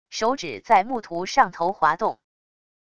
手指在木图上头滑动wav音频